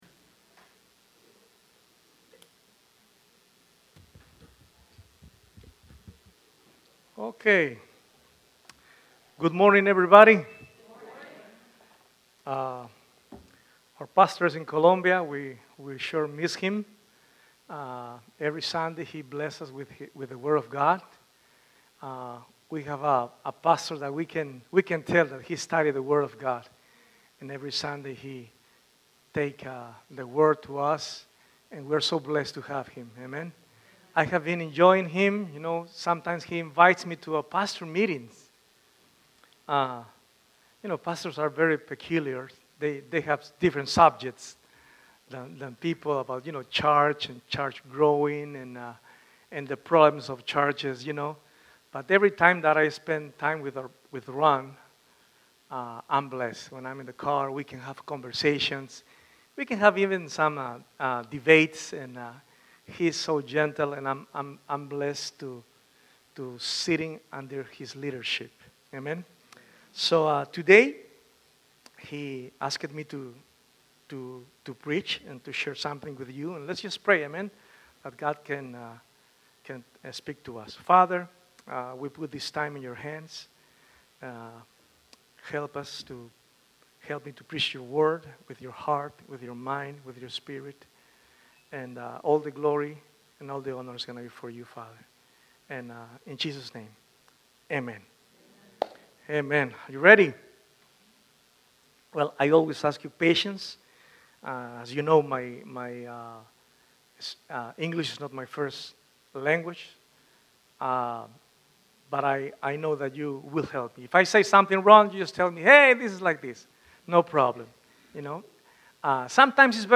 by Calvary Chapel Leesburg | Nov 9, 2025 | Sermons